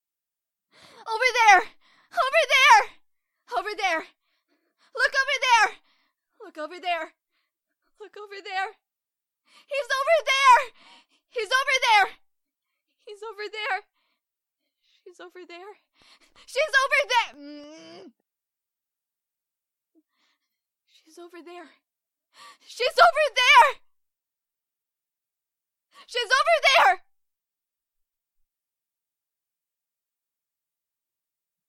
使用USB Mic和Audacity录制。
标签： 女孩 性感 得到的 语音 英语 女性 疯了 美国 声乐 生气 获取 大呼小叫 关闭 女人
声道立体声